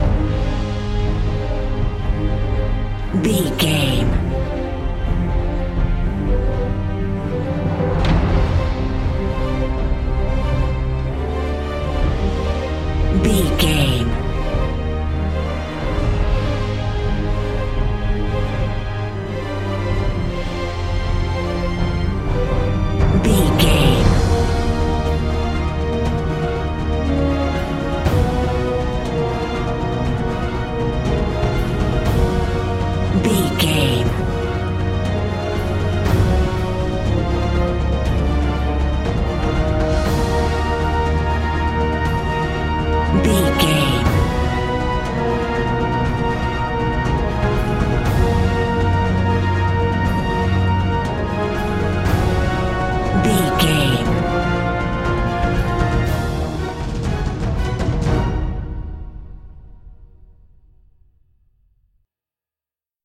Fast paced
In-crescendo
Aeolian/Minor
strings
drums
orchestral
orchestral hybrid
dubstep
aggressive
energetic
intense
bass
synth effects
wobbles
driving drum beat
epic